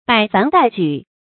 百凡待舉 注音： ㄅㄞˇ ㄈㄢˊ ㄉㄞˋ ㄐㄨˇ 讀音讀法： 意思解釋： 無數事情都等待興辦。